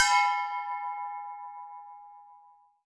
auction bell1.wav